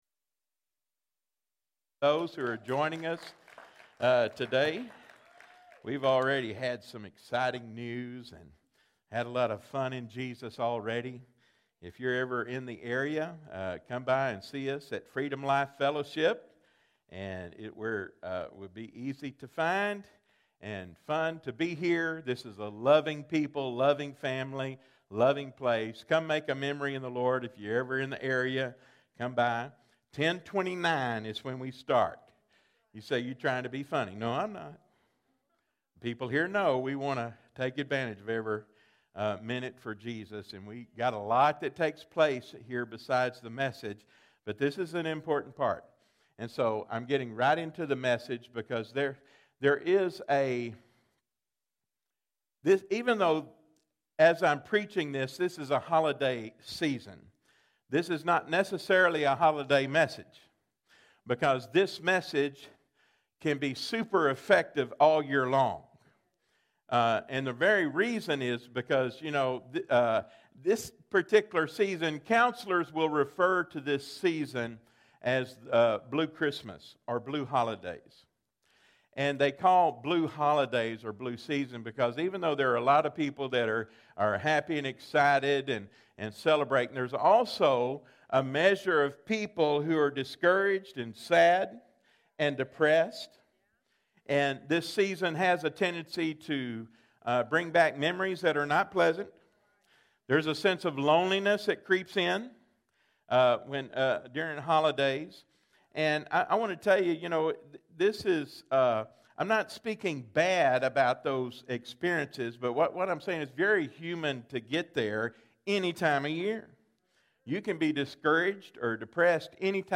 Freedom Life Fellowship Live Stream